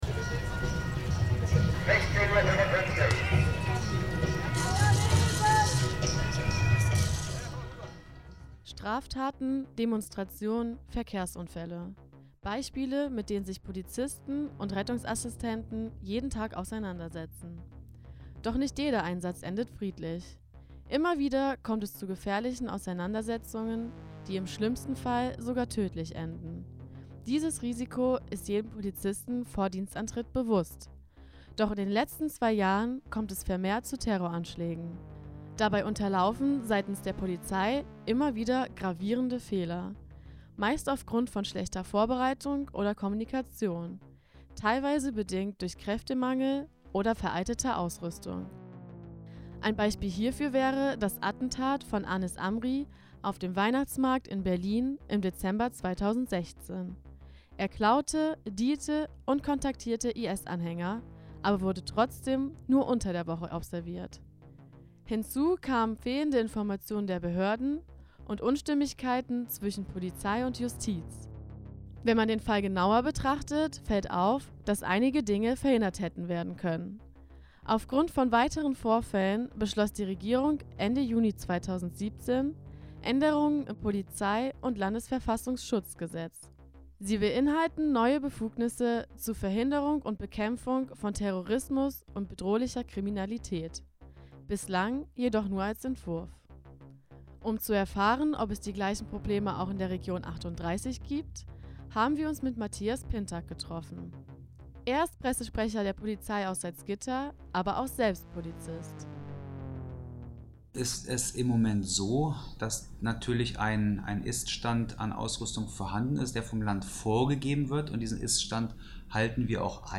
Deutsche Politik antwortet mit der Ausweitung der Befugnisse von Polizei und Rettungsdienst. Campus38 fragt Beamte und Politiker aus der Region.